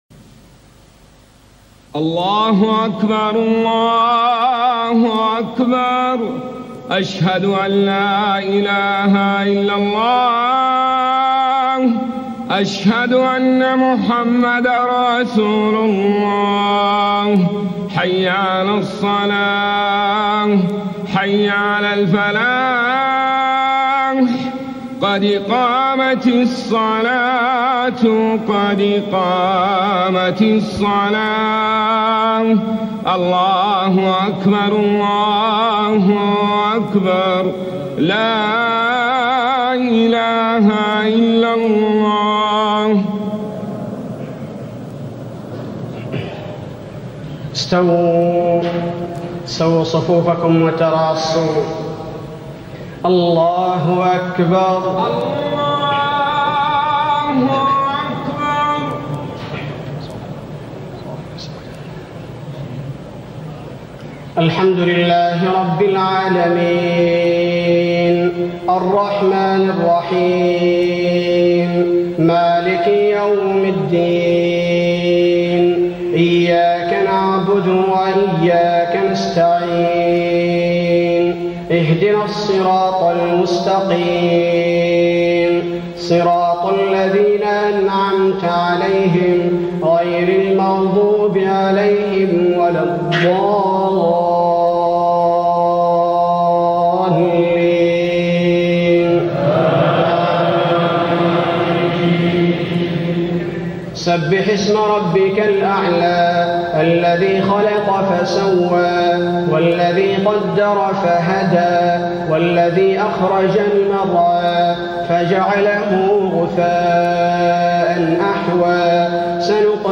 صلاة الجمعة 24 شوال 1421هـ سورتي الأعلى و الغاشية > 1421 🕌 > الفروض - تلاوات الحرمين